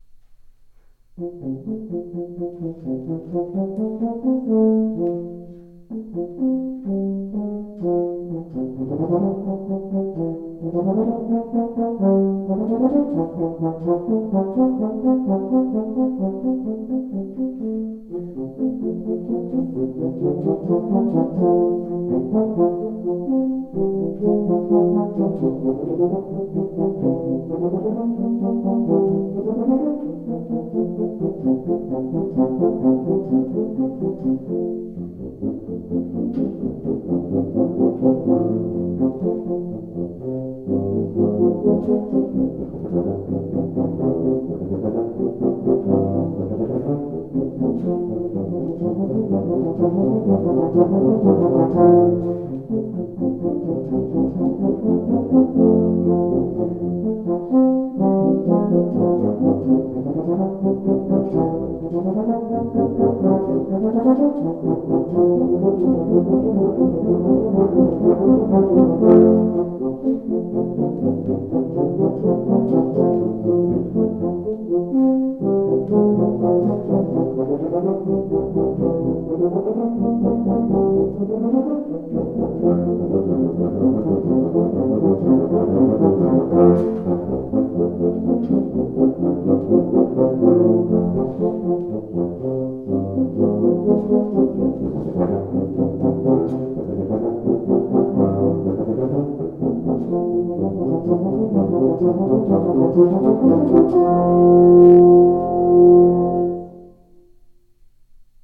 Voicing: Low Brass Trio